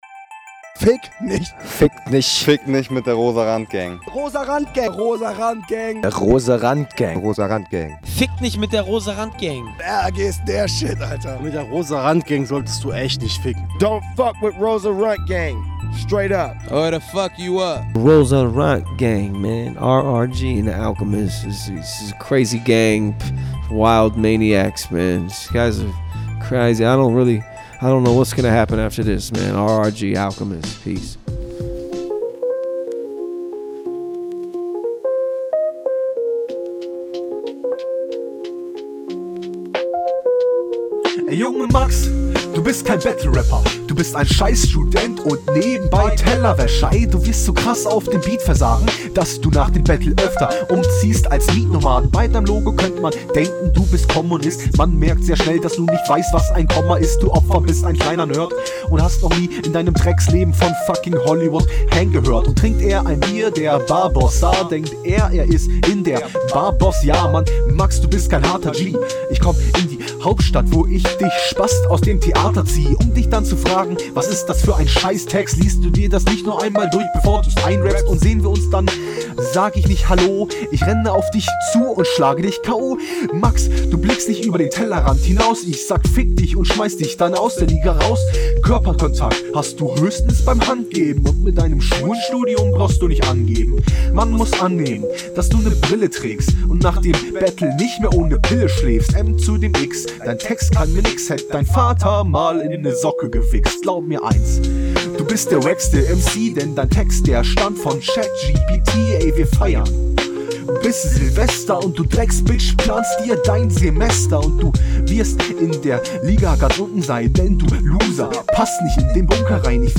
Lustigerweise kommst du besser auf den Beat deines Gegners als auf deinem eigenen klar.